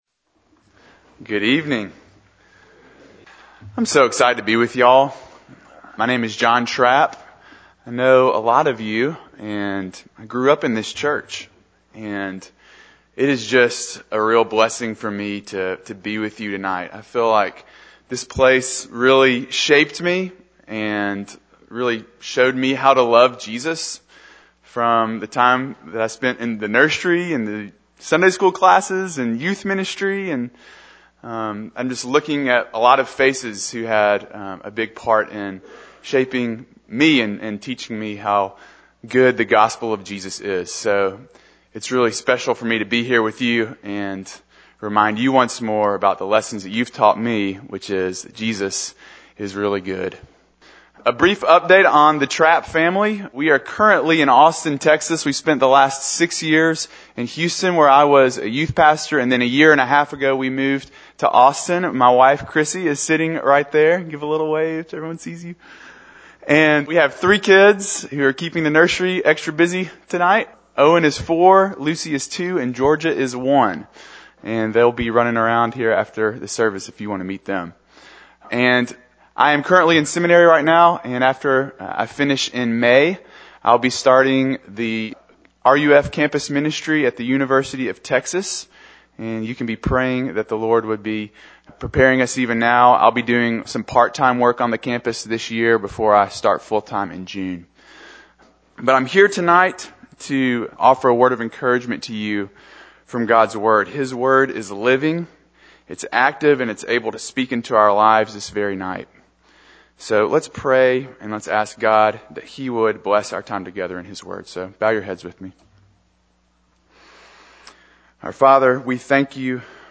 Service Type: Sunday Mornin